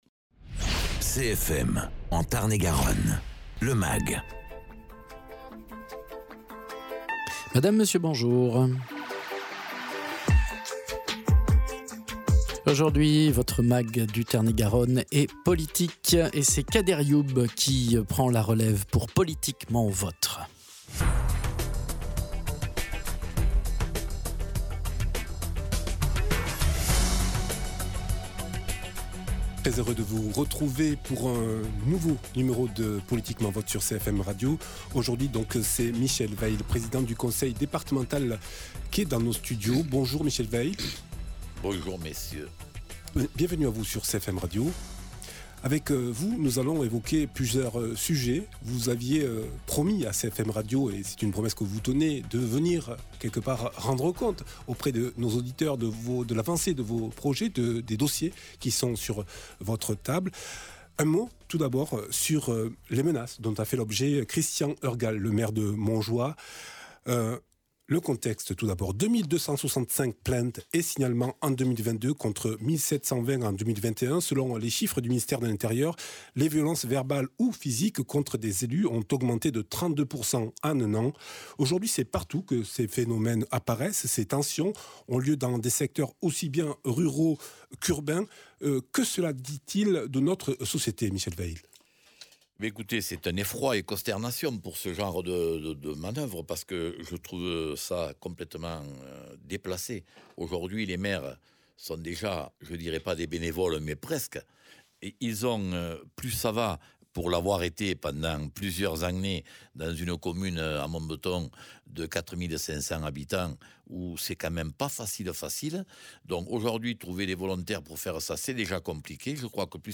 Invité(s) : Michel Weill, président du Conseil départemental de Tarn-et-Garonne